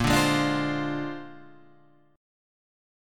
A#9 chord {6 5 3 5 3 4} chord